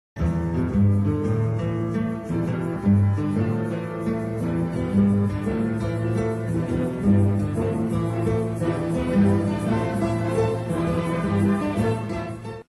Gitarren mit Orchester